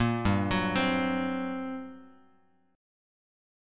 GmM11 chord